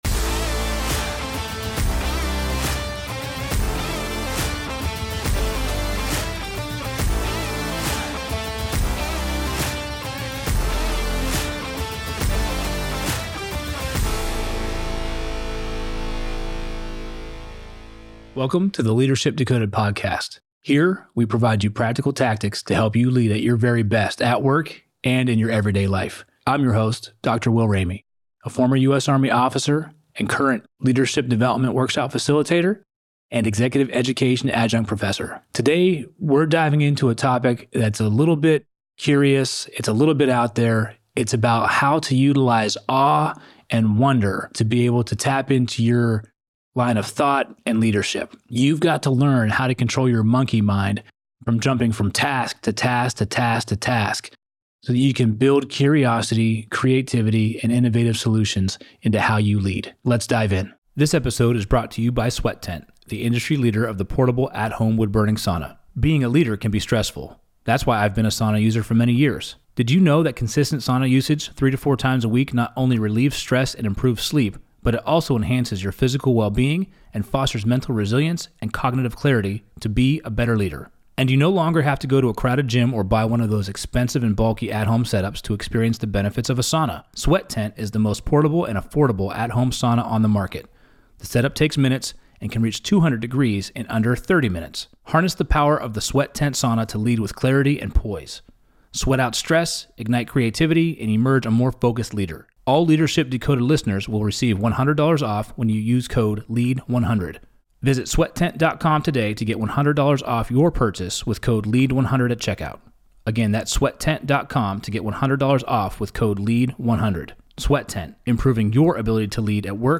in the Loop Internet studio